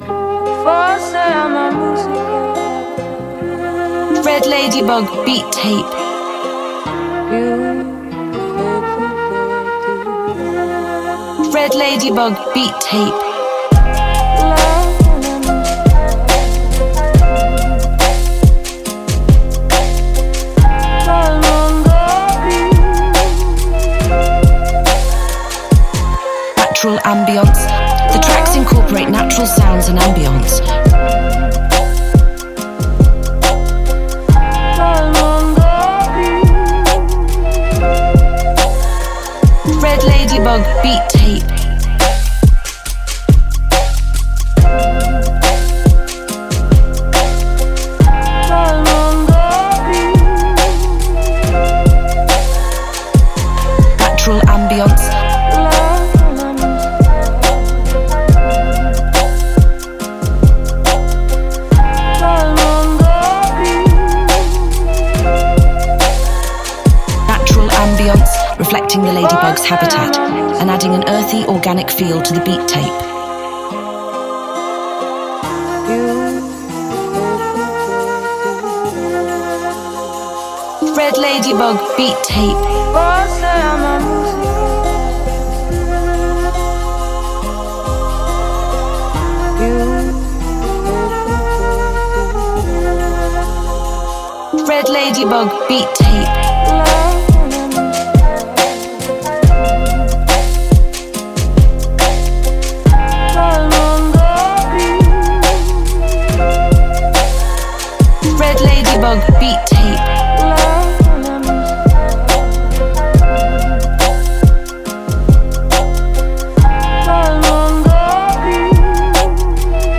2. Boom Bap Instrumentals